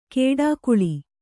♪ kēḍākuḷi